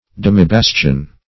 Search Result for " demibastion" : The Collaborative International Dictionary of English v.0.48: Demibastion \Dem"i*bas"tion\ (?; 106), n. [Cf. F. demi- bastion.]